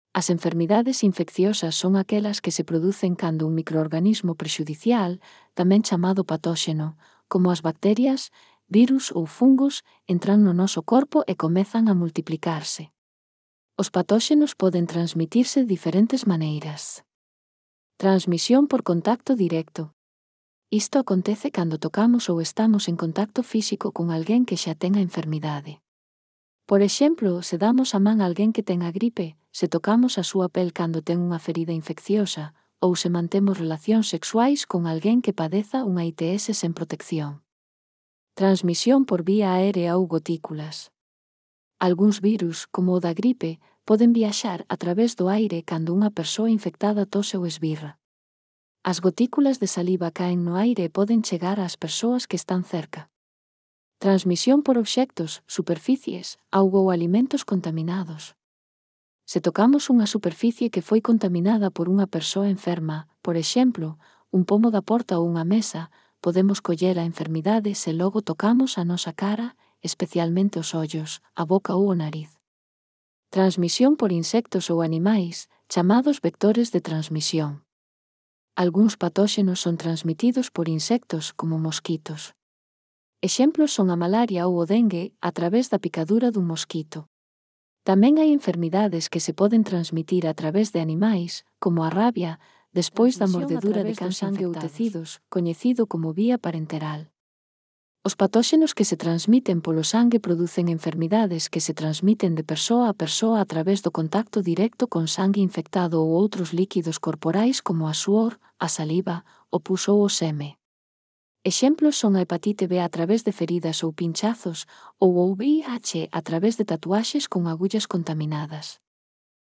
Lectura facilitada